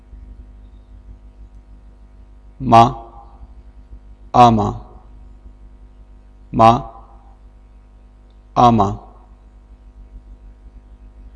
Consonnes - Sujet #4